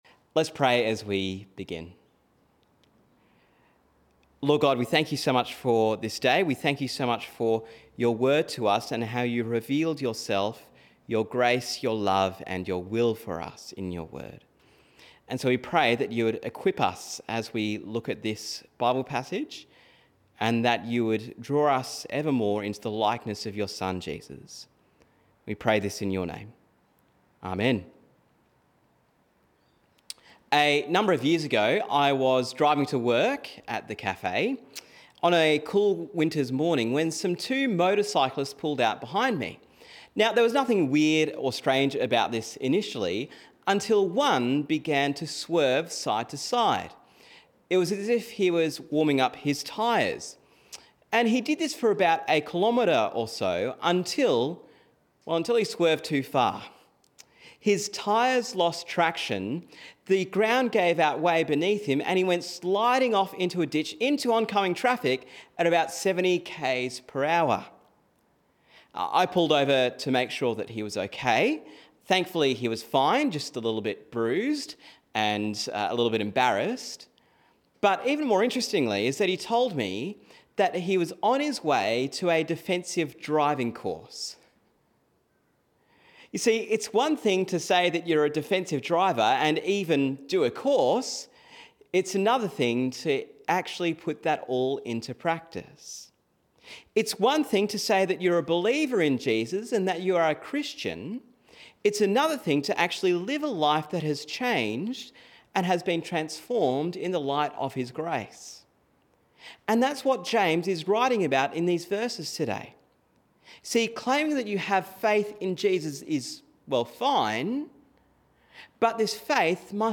Sermon on James 2:14-26